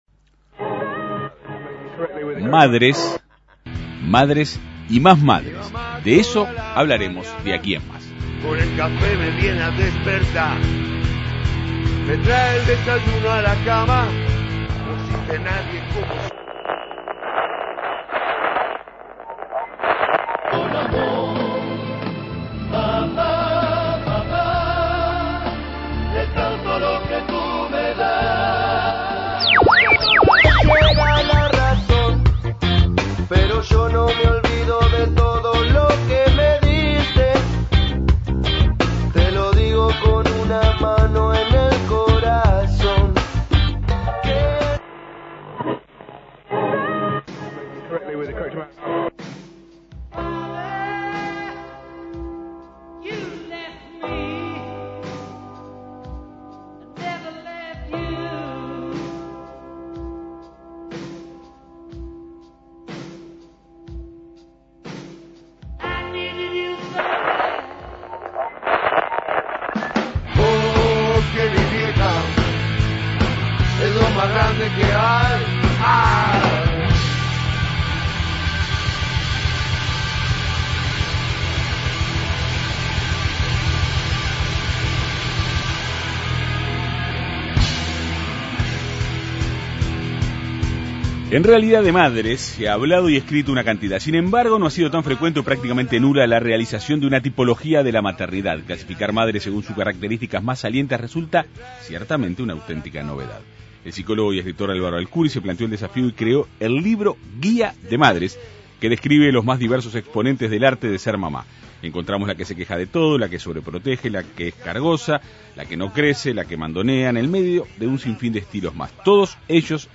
El autor dialogó en la Segunda Mañana de En Perspectiva.